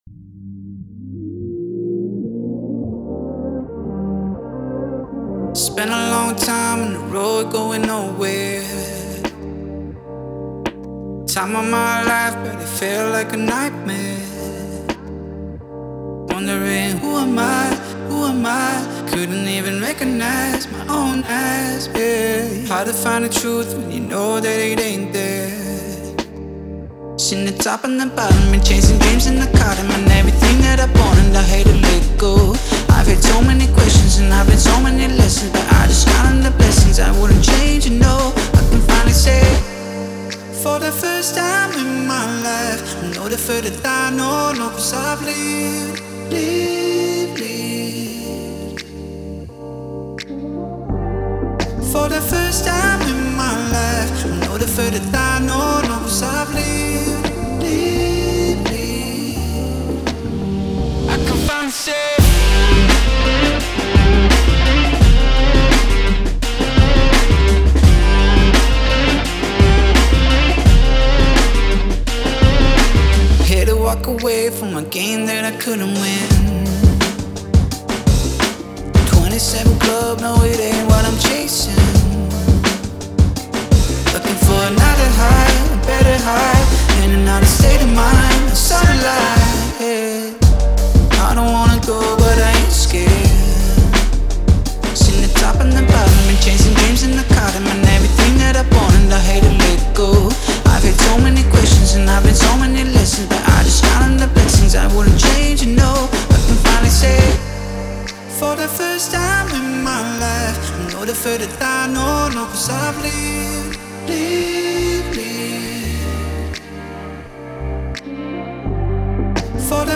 который сочетает в себе элементы поп и инди-музыки.